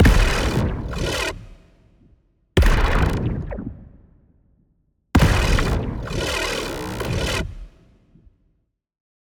Impact Blast 2
Impact-Blast-02-Example.mp3